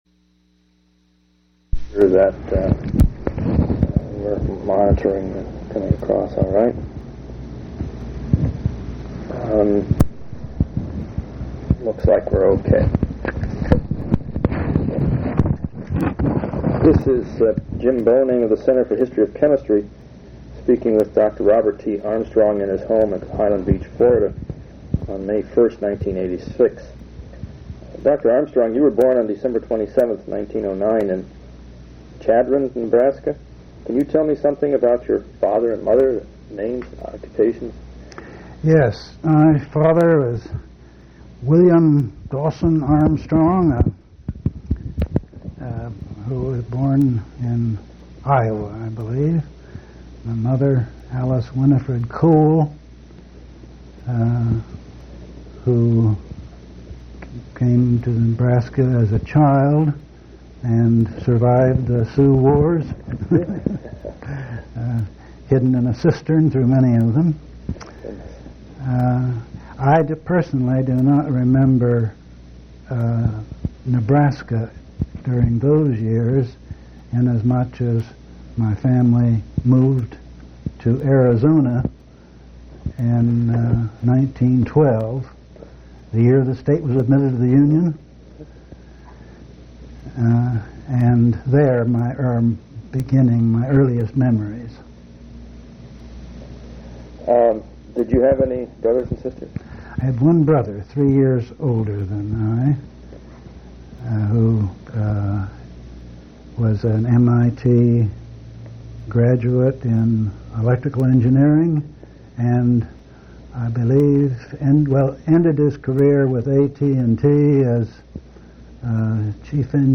Oral histories
Place of interview Florida--Highland Beach